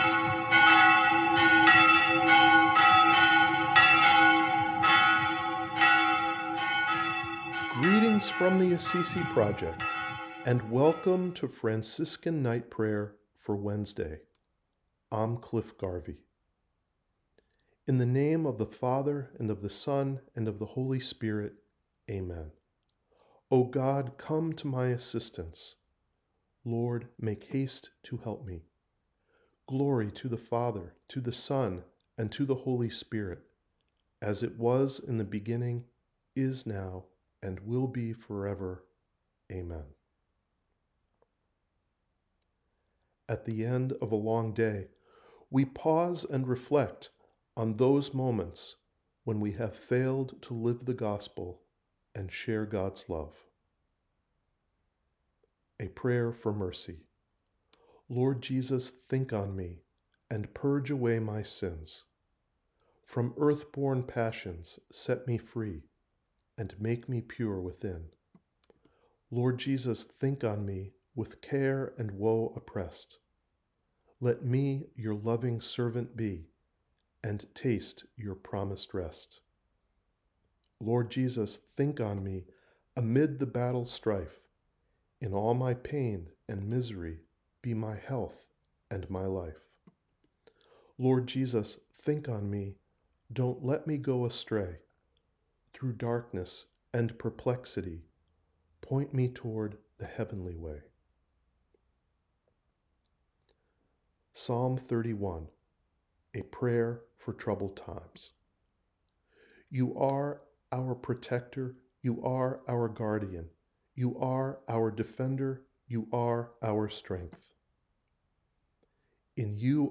AP NIGHT PRAYER